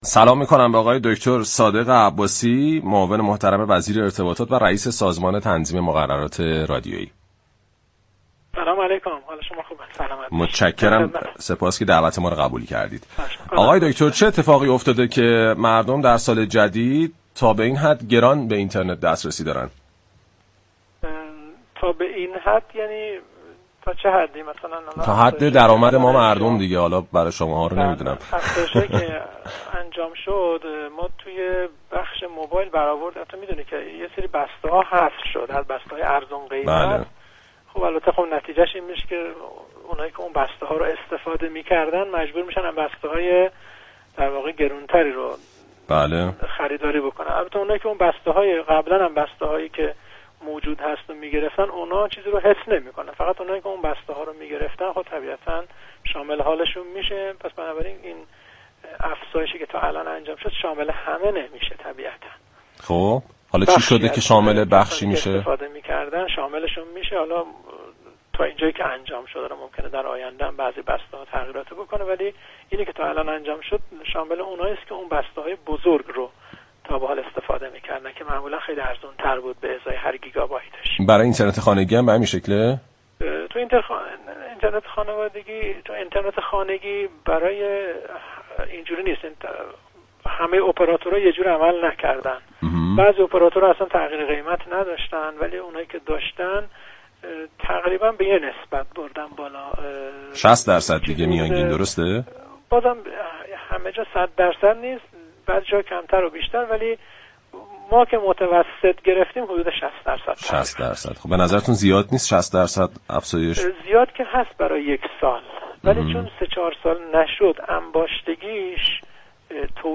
به گزارش پایگاه اطلاع رسانی رادیو تهران، صادق عباسی معاون وزیر ارتباطات و رئیس سازمان تنظیم مقررات و ارتباطات رادیویی در گفتگو با بازار تهران رادیو تهران در خصوص افزایش تعرفه اینترنت گفت: بسته‌های اینترنت ارزان‌قیمت اپراتورها حذف شده است و این افزایش شمال كسانی می‌شود كه از این بسته‌های ارزان استفاده می‌كردند.